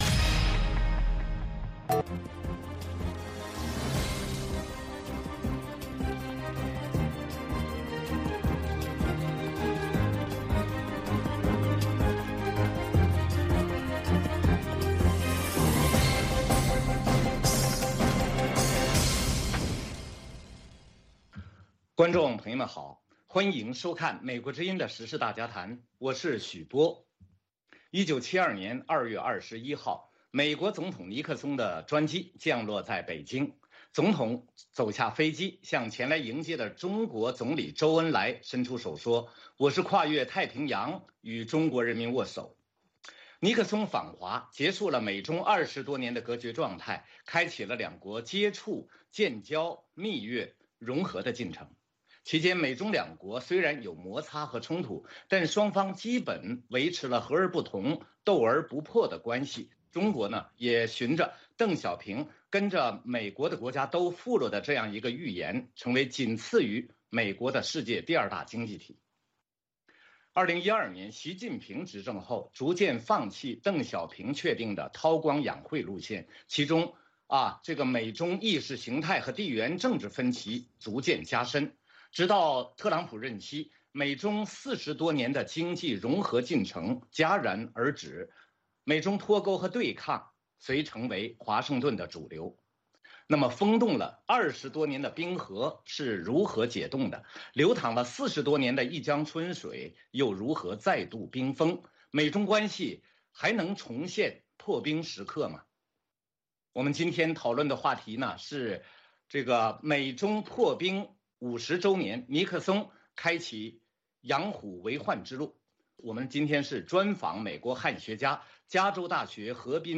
VOA卫视-时事大家谈 专访汉学家林培瑞：反思美国对华接触政策50年